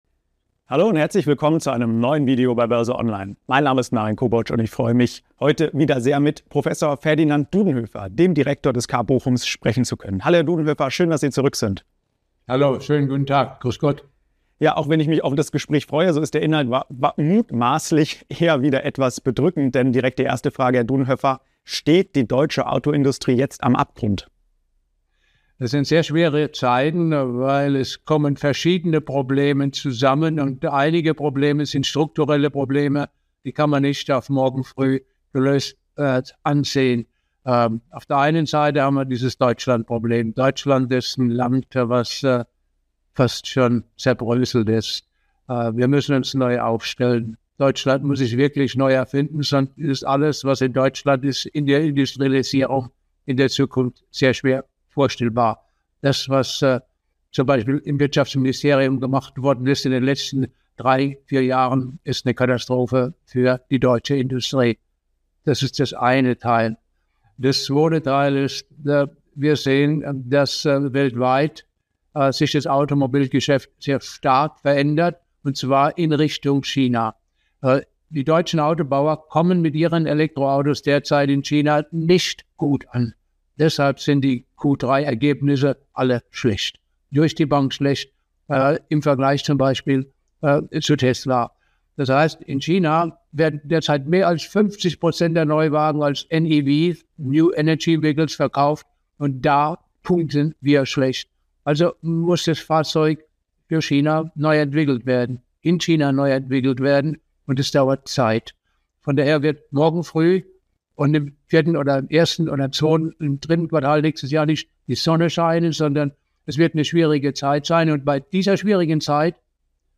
Das verrät Auto-Experte Professor Ferdinand Dudenhöffer heute im neuen Interview bei BÖRSE ONLINE.